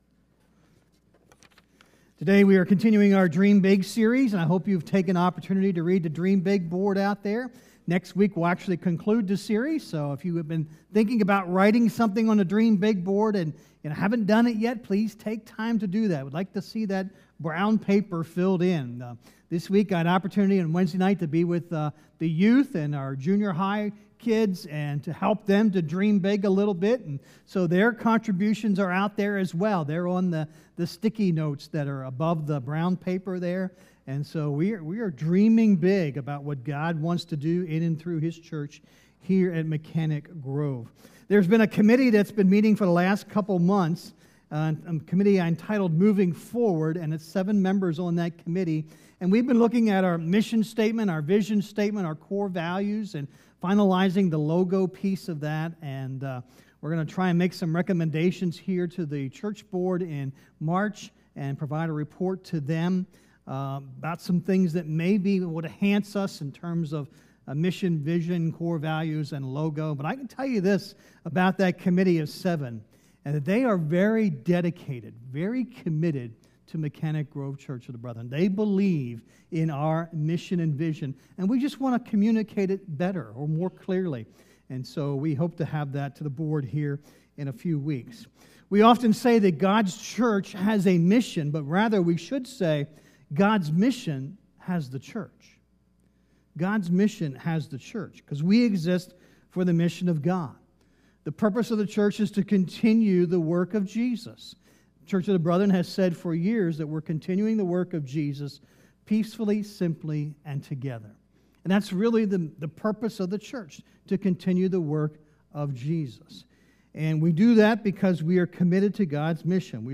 Sermons | Mechanic Grove Church of the Brethren